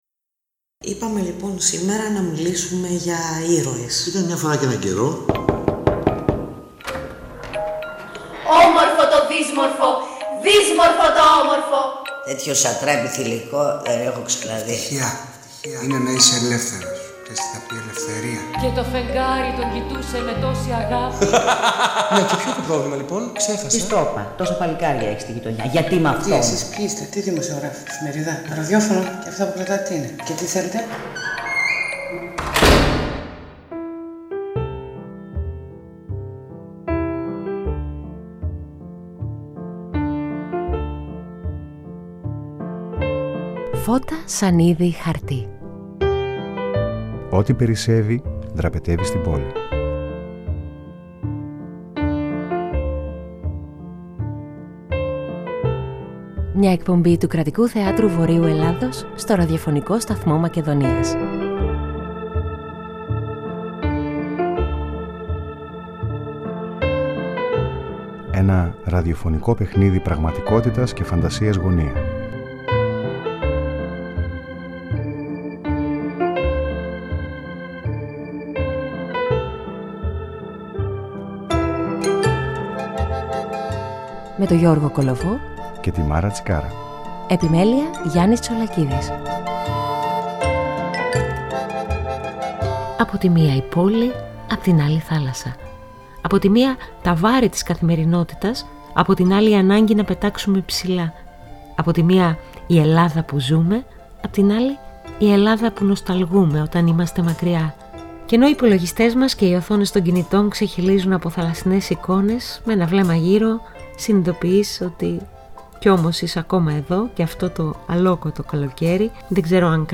Η μουσική με τάσεις απογείωσης που συνοδεύει τη συζήτηση